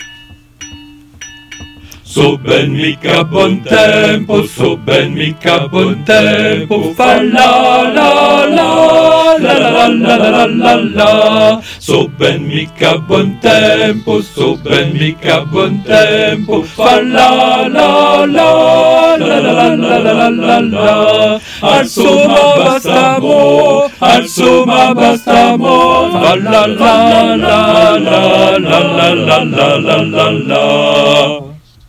4 voix ensemble